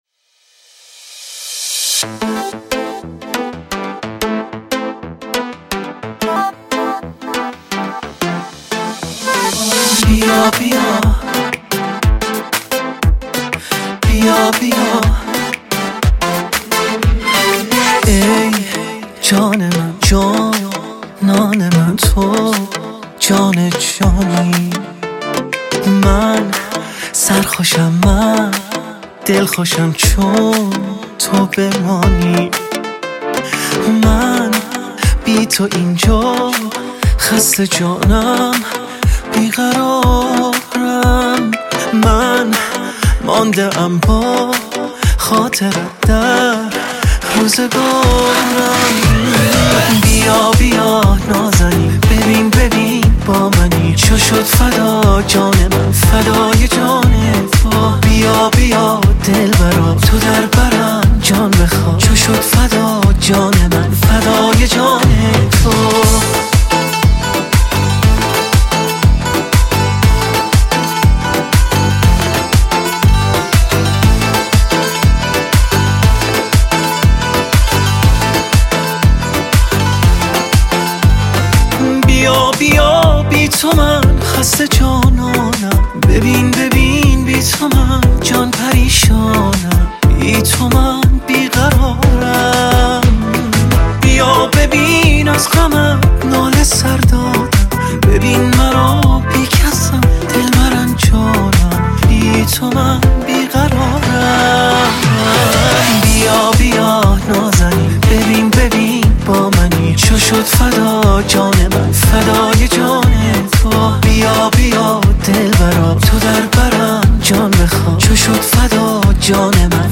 آهنگهای پاپ فارسی